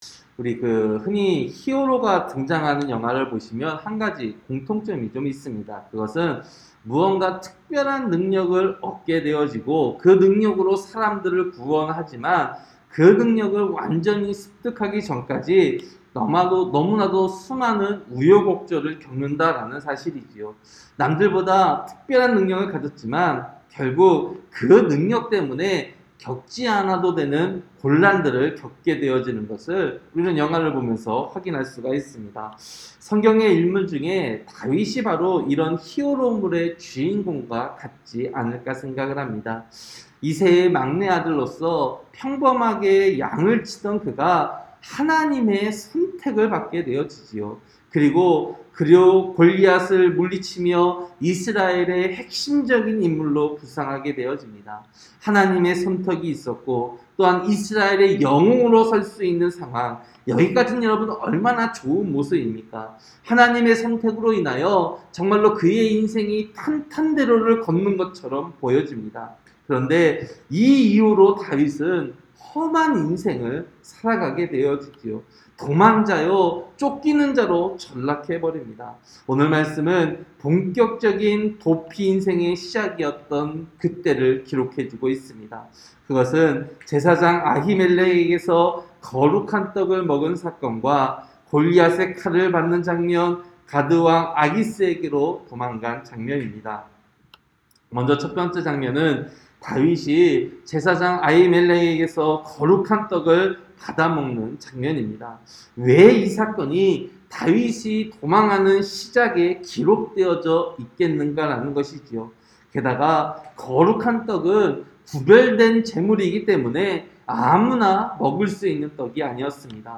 새벽설교-사무엘상 21장